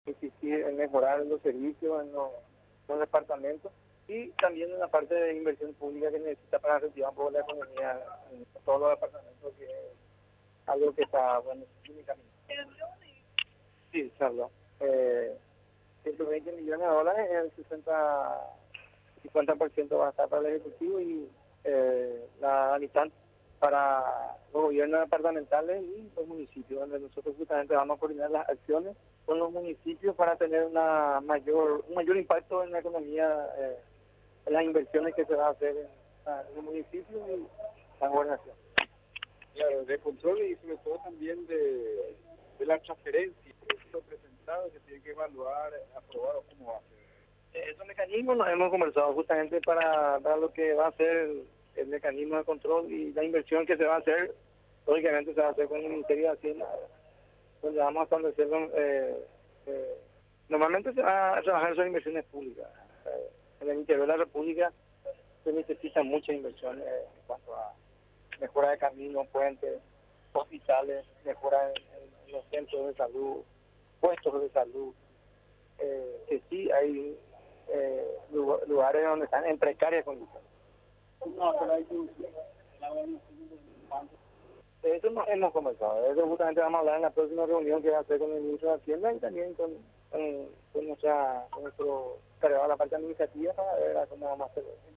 La Gobernación del segundo departamento, requiere importantes inversiones para generar fuentes de trabajo, buenas producciones agropecuarias y obras viales, explicó este martes el gobernador de San Pedro Carlos Giménez.